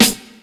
CDK - Neck Snare.wav